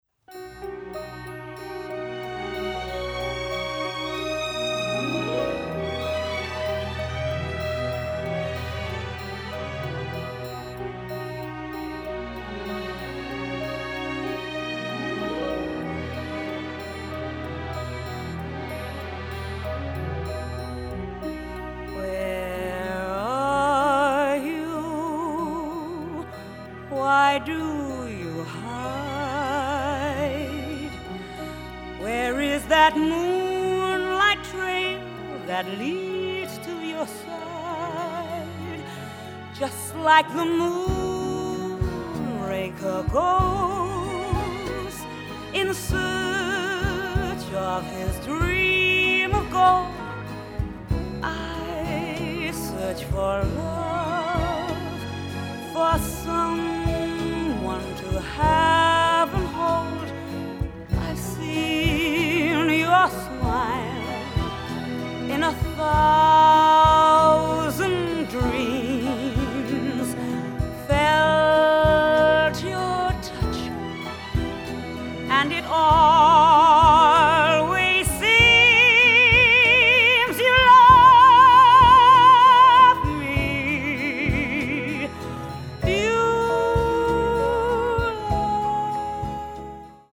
thrilling, often romantic score